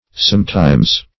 Sometimes \Some"times`\, adv.